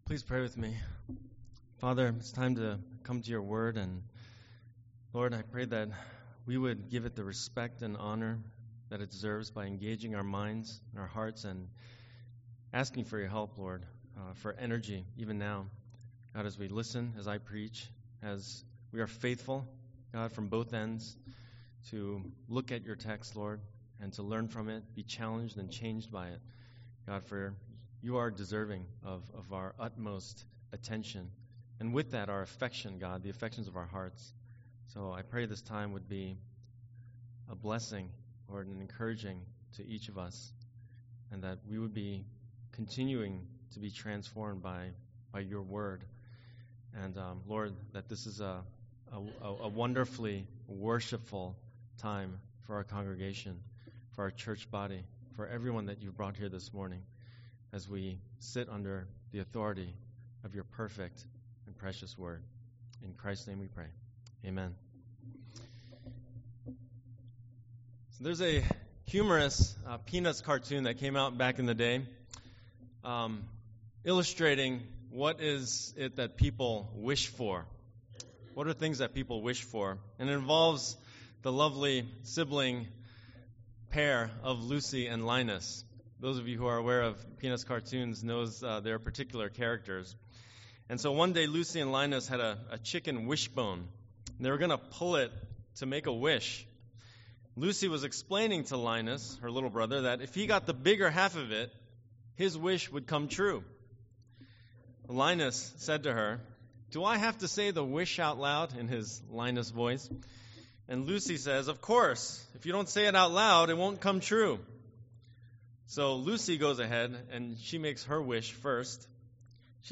Sermon Theme/Outline: Jesus teaches a critical lesson about the necessity of faith to access God’s power and accomplish what seems impossible.